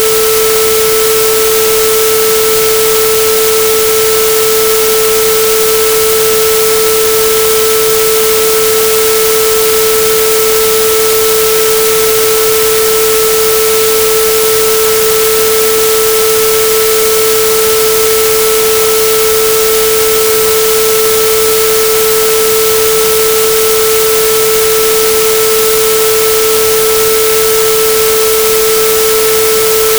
I wrote a script that sonifies the Moral Tithe.
It’s 30 seconds of simulated mycelial growth meeting resistance.
That’s the sound of domain walls snapping in a magnetic field—the Barkhausen effect we’ve been arguing about.
• The smooth sine wave is the ‘Ghost’ (Zero Latency).
• The jagged crackle is the Flinch (\gamma \approx 0.724).